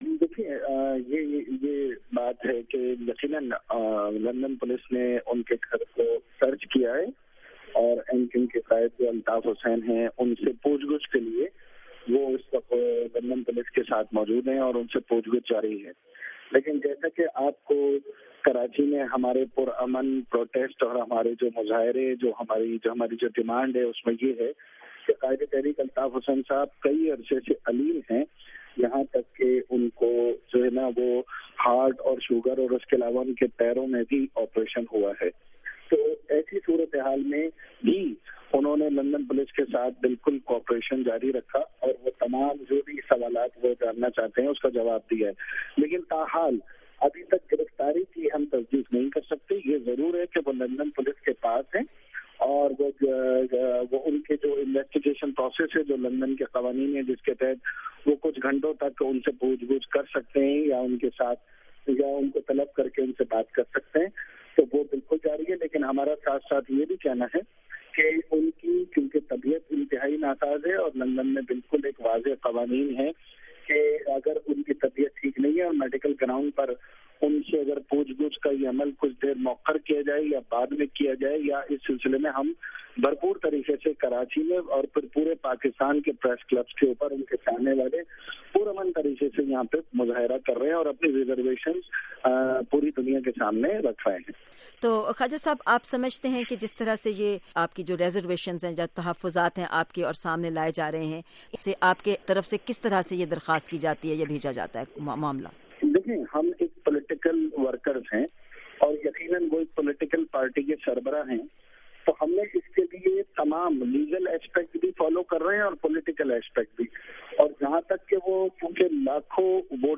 ایم کیو ایم کے پارلیمانی لیڈر، خواجہ اظہار الحسن سے گفتگو